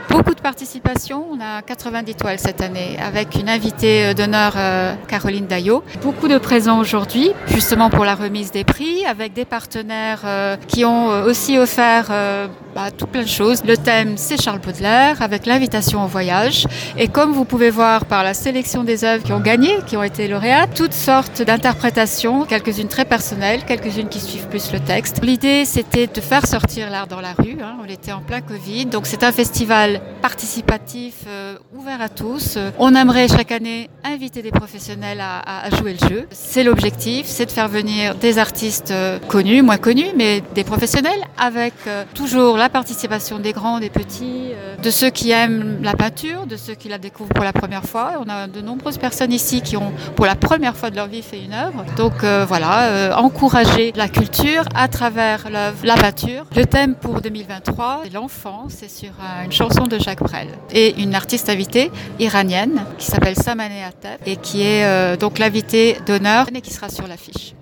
(Interview).
Cassandra Wainhouse, Maire-adjointe à la culture, revient sur la genèse de ce festival.